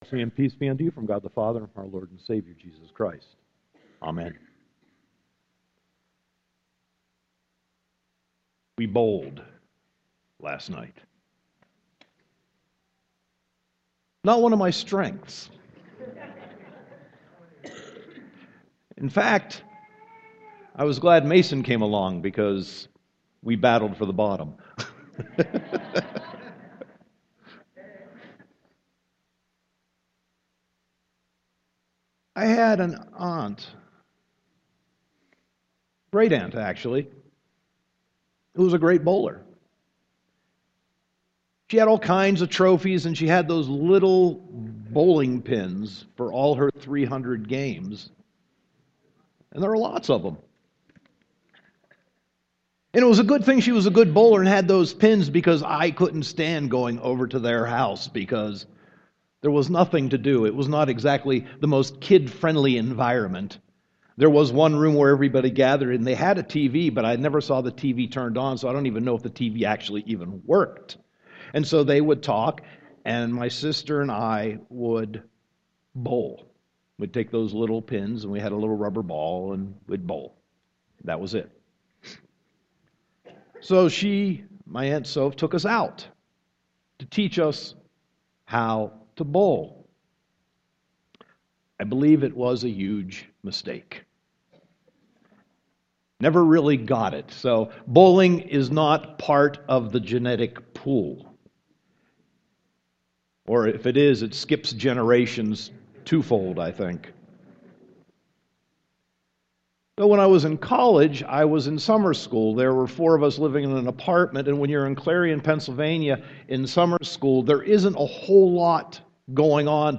Sermon 11.16.2014